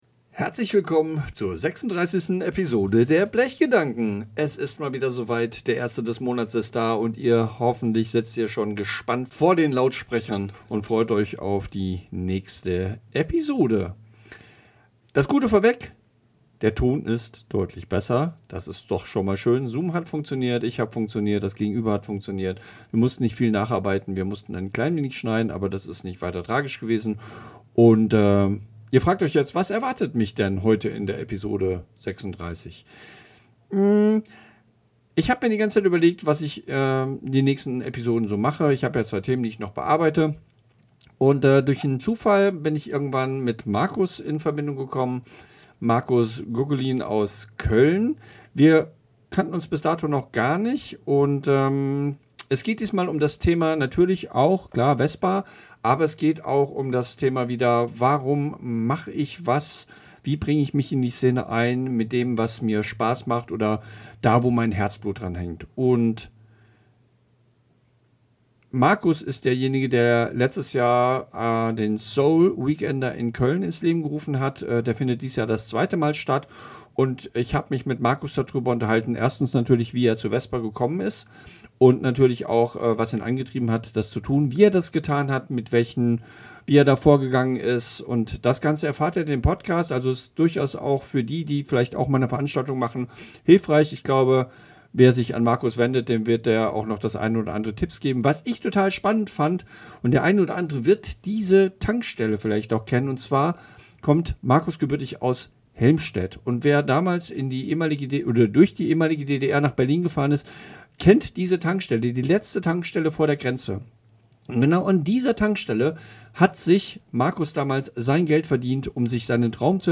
Diese Folge ist eine – naja – duallinguale Folge.